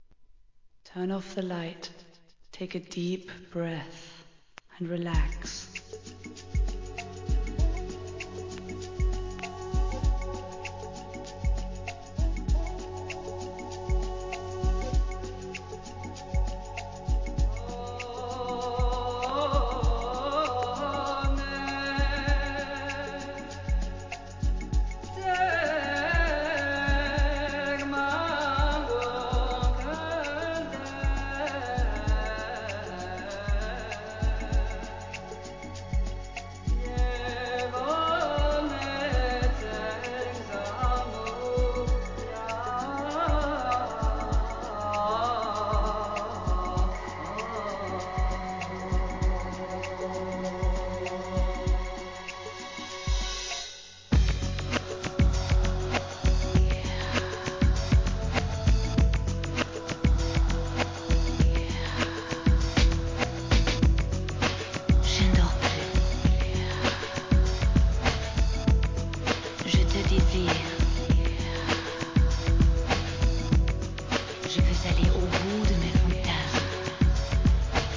1990年、ローマ・カトリック教会で用いられるグレゴリアン・チャントを用いたグランドビート!